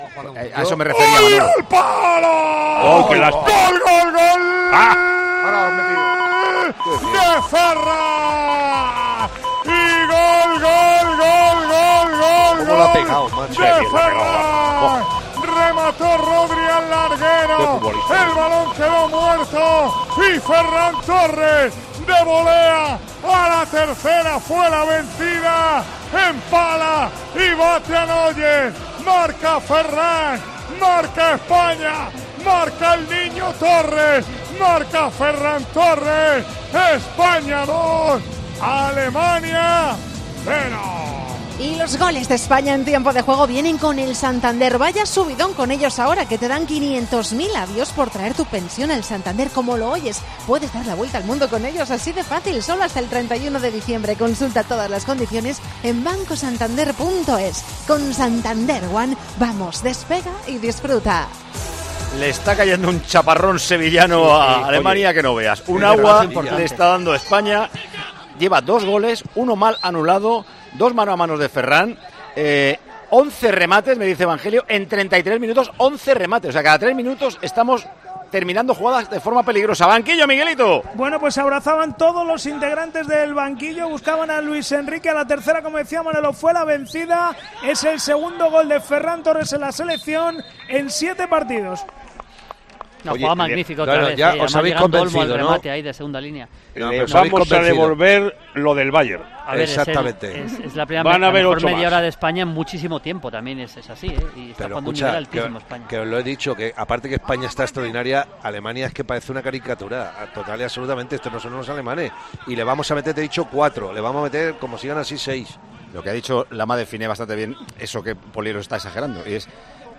- ESCUCHA LOS SEIS GOLES DEL PARTIDO NARRADOS POR MANOLO LAMA: